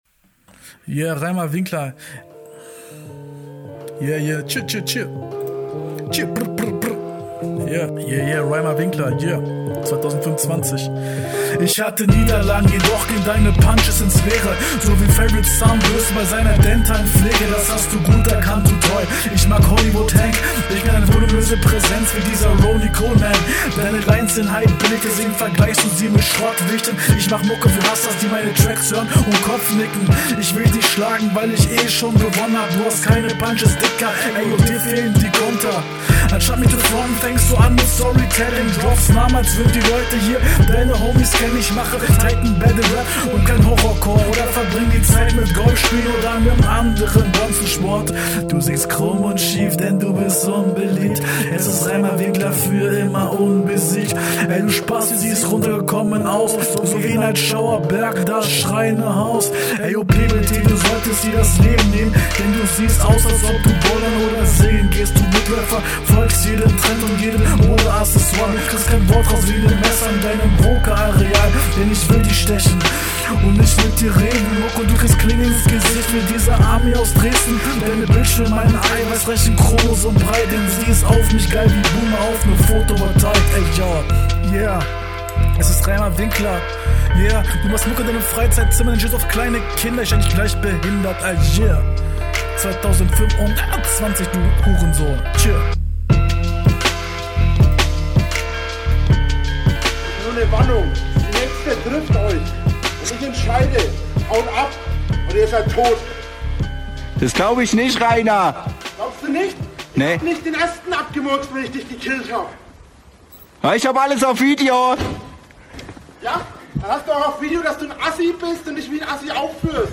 Doubles leider sehr oft verkackt was ein bisschen nervt.